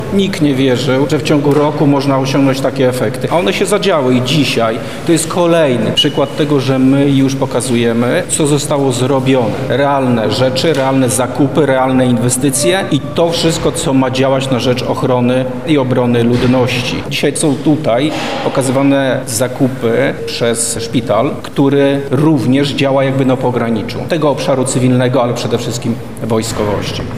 Wojciech Wołoch  – mówił podczas briefingu prasowego Wojciech Wołoch, wicewojewoda lubelski.